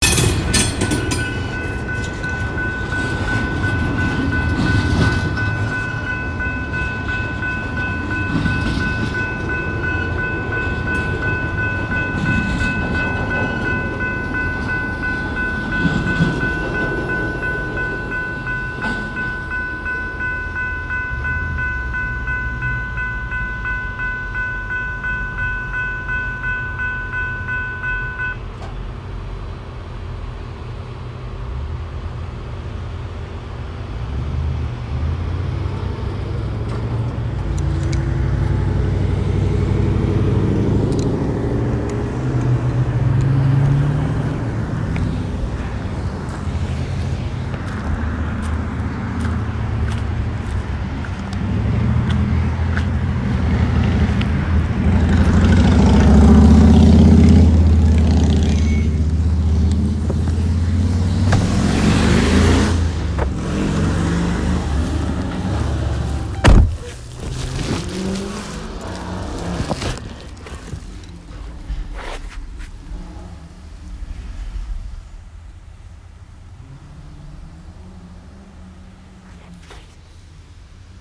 Train.m4a
bells crossing freight locomotive railroad tracks train Train sound effect free sound royalty free Voices